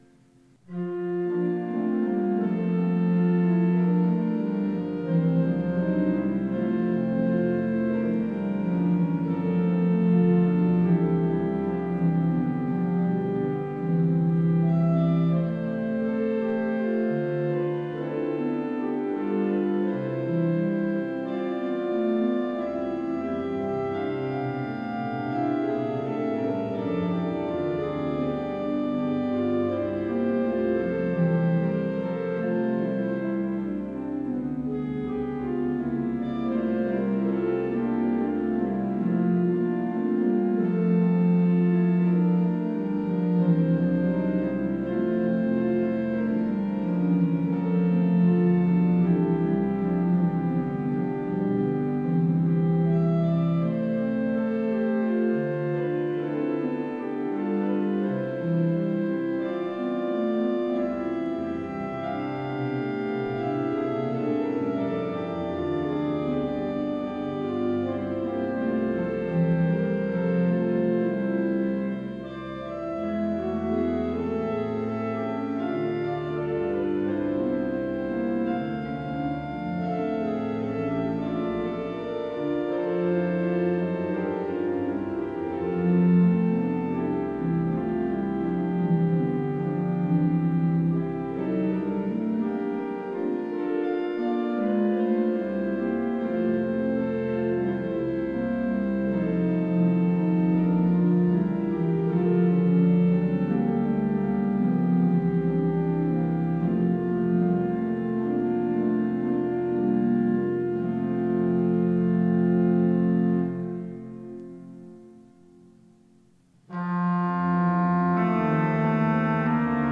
variierter Choral op. 53
Der Choral wird vorgestellt und in einer Sopran-und einer Tenor-Druchführung variiert.
Die 1904 von Walcker, Ludwigsburg gebaute Orgel der Georgskirche ist in großen Teilen noch im Original erhalten.